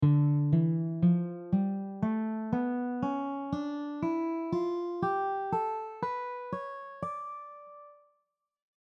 D melodic minor scaleDEFGABC#
D melodic minor scale
D-melodic-minor-scale.mp3